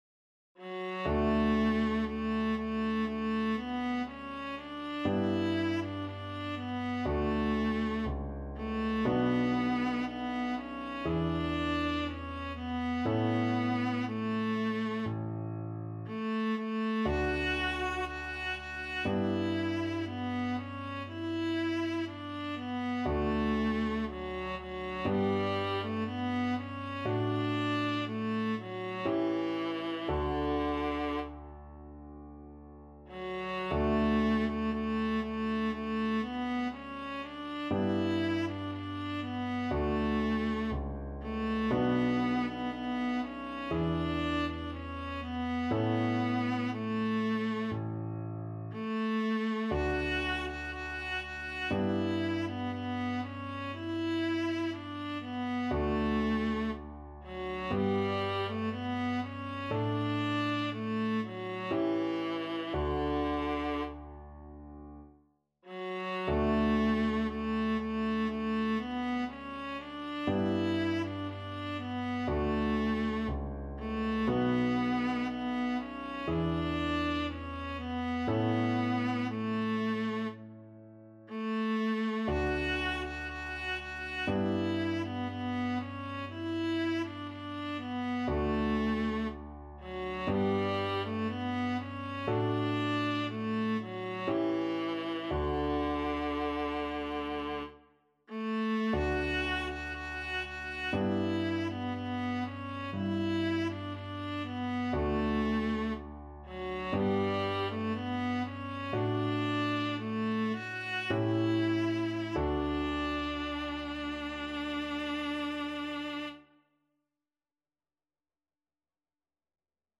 ~ = 100 Adagio
4/4 (View more 4/4 Music)
Classical (View more Classical Viola Music)